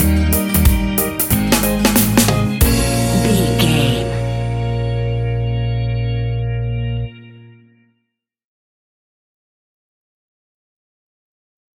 A fast and speedy piece of Reggae music, uptempo and upbeat!
Aeolian/Minor
B♭
laid back
off beat
drums
skank guitar
hammond organ
percussion
horns